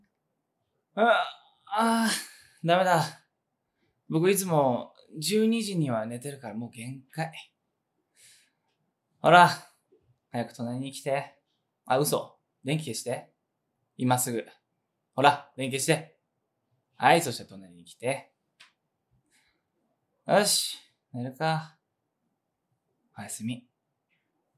12,250投稿を記念して、メンバーのおやすみボイスを2025年12月31日までお届けします。
リョウガおやすみボイス.wav